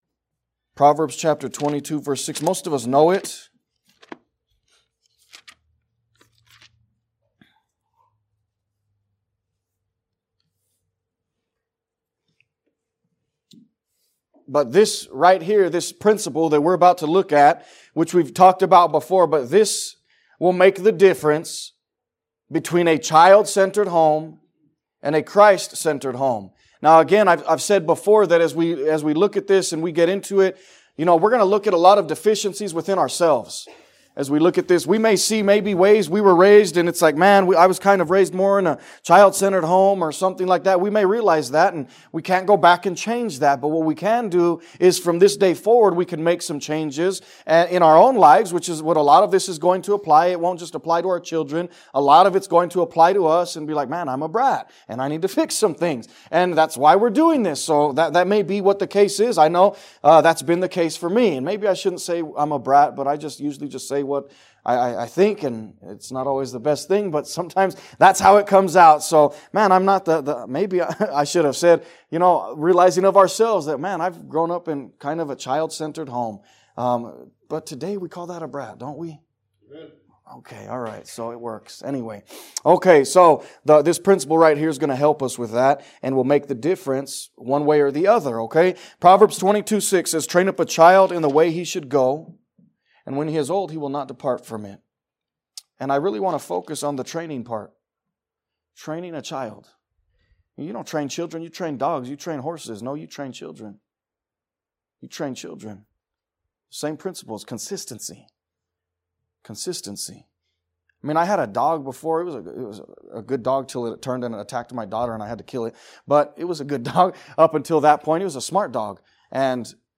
A message from the series "Stand Alone Messages."